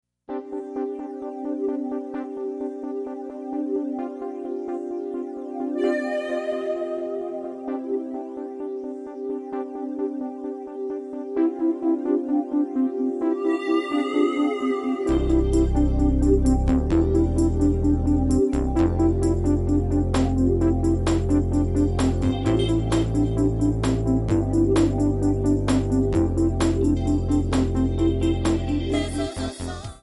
Backing track Karaoke
Pop, Rock, Musical/Film/TV, 1990s